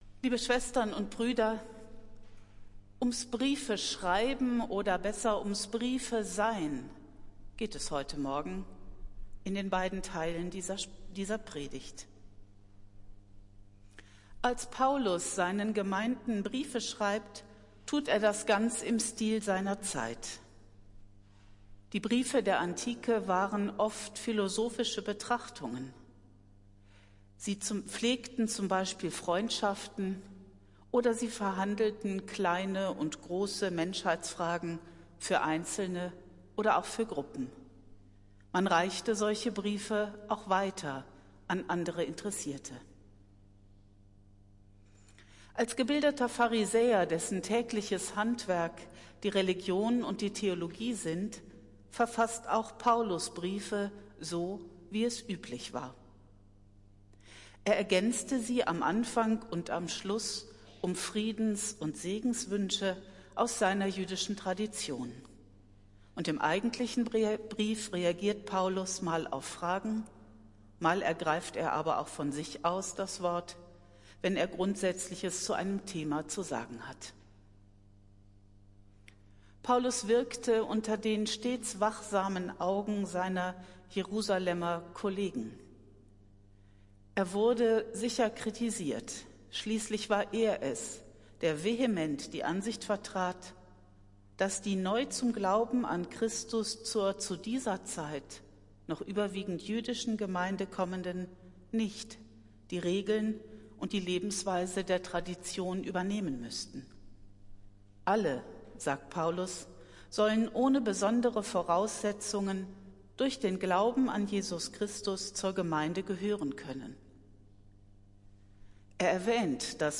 Predigt des Gottesdienstes aus der Zionskirche vom Sonntag, den 13. Oktober 2024